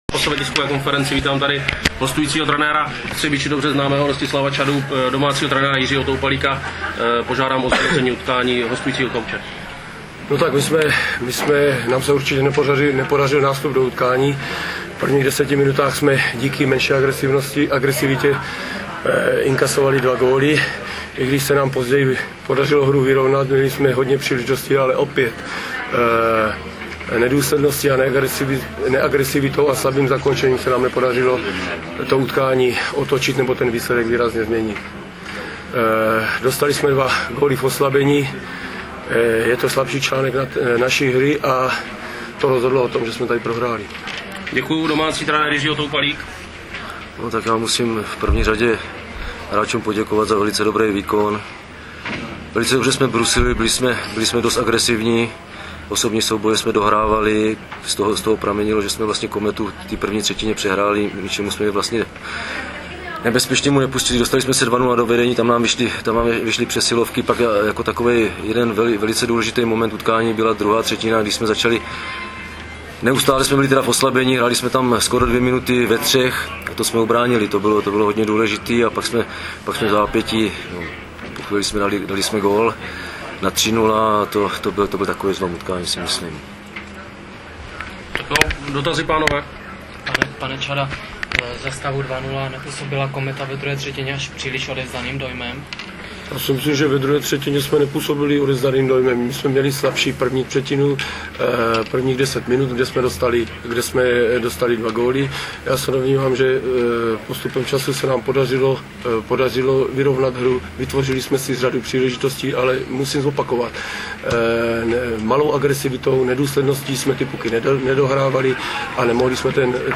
Tisková konference
Ohlasy trenérů
Tisková_konference_428.WMA